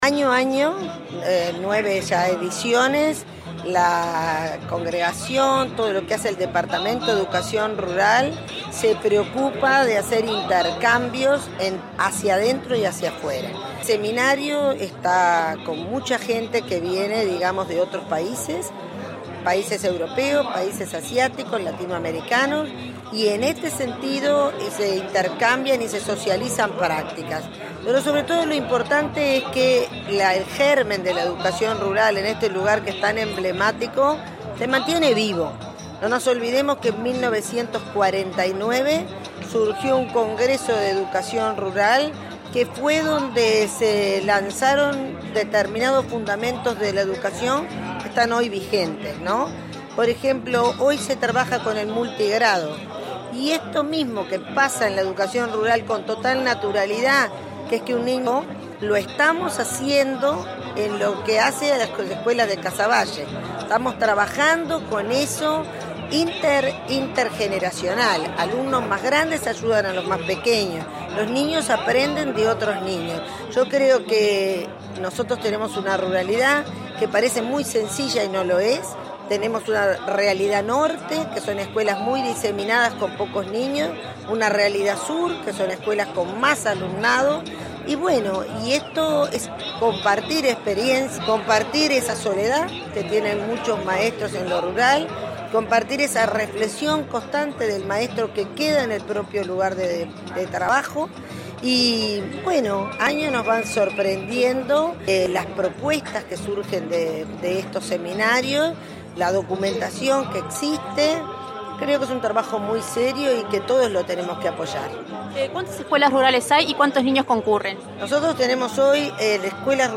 Primaria prevé incorporar enseñanza de inglés en todas las escuelas rurales del país, anunció este jueves en Canelones la directora, Irupé Buzzetti, durante un seminario sobre educación rural en el que se reconoció el trabajo del Programa de Salud Bucal. Dijo que en estos centros trabajan con multigrado y que alumnos más grandes ayudan a los más pequeños.